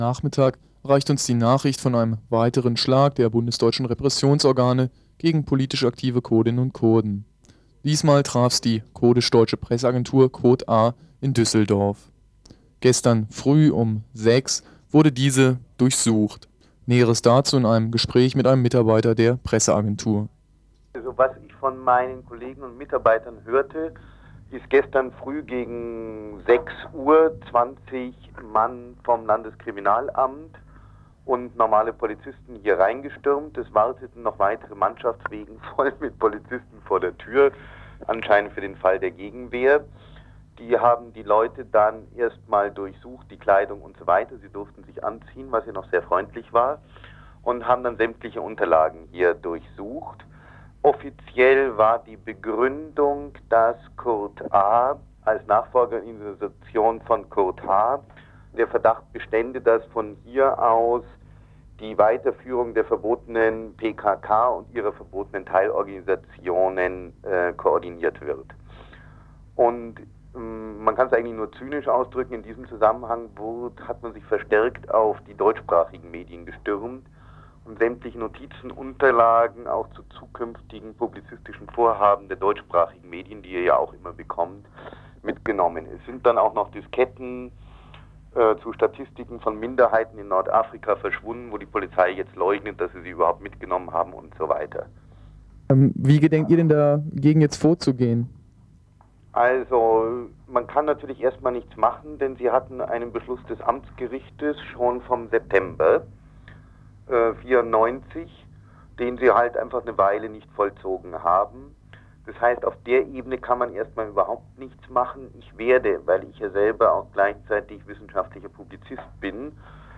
Mi 23.11.94 1 Durchsuchung der kurdisch-deutschen Presseagentur Kurd-A in Düsseldorf. Interview mit Mitarbeiter.